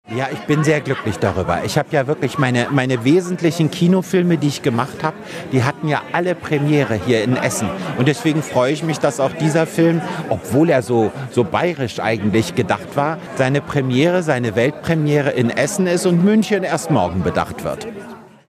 Hier in der Stadt waren bisher alle Premieren seiner bedeutenden Filme, sagt Kerkeling im Radio Essen-Interview vor der Lichtburg.